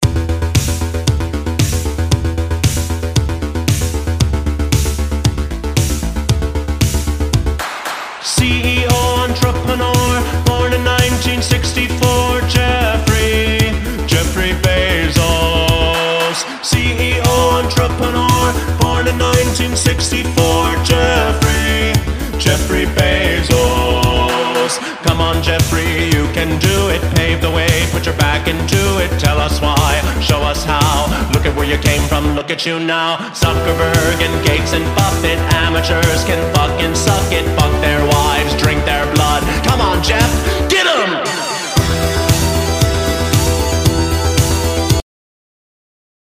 You Just Search Sound Effects And Download. tiktok funny sound hahaha Download Sound Effect Home